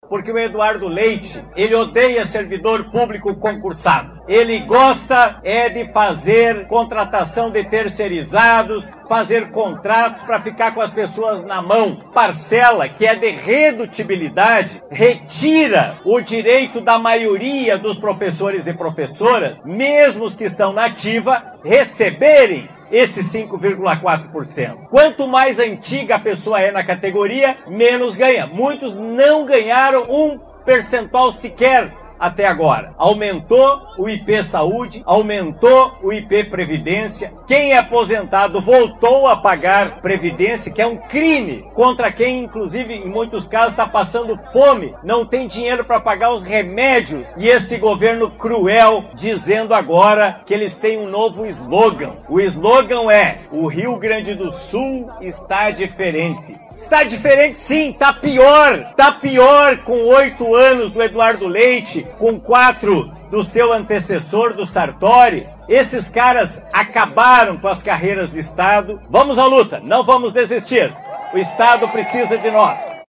O deputado Jeferson Fernandes, da região de Santa Rosa, criticou a posição do governo e se posicionou favorável a categoria.